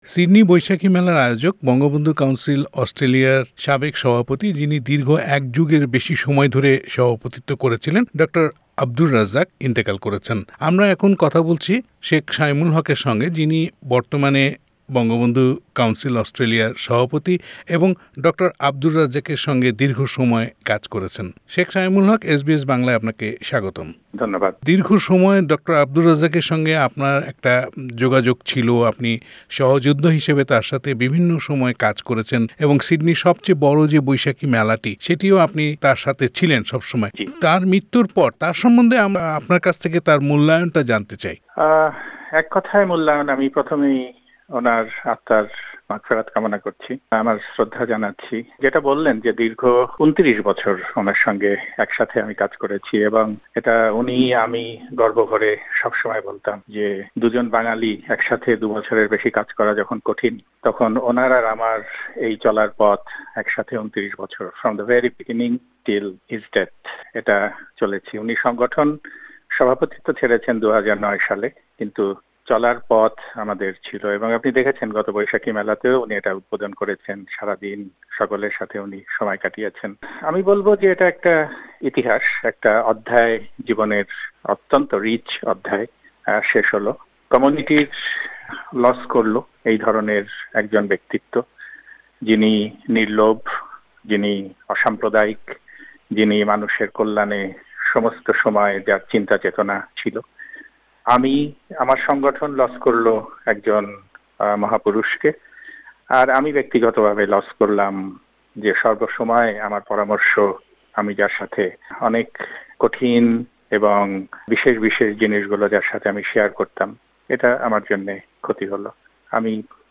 এস বি এস বাংলার সঙ্গে আলাপচারিতায় তুলে ধরেন সেই সব স্মৃতিকথা।